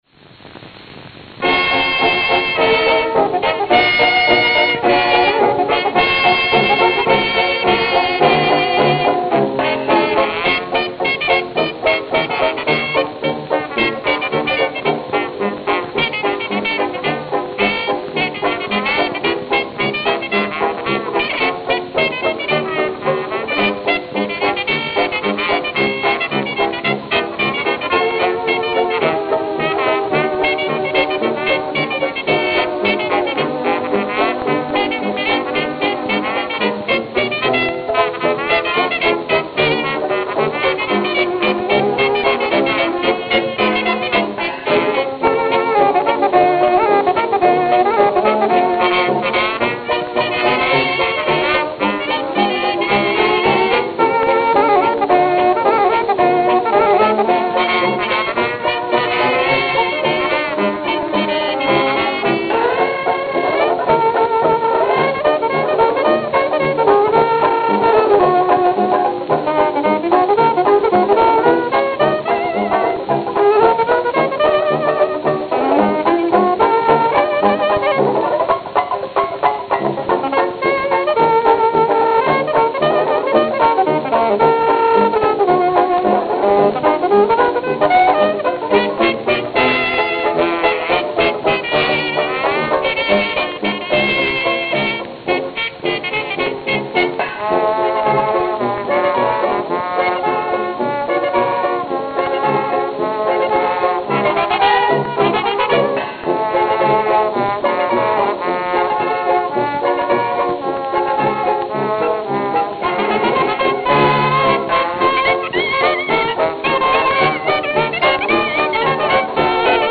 Note: Played at 80 RPM.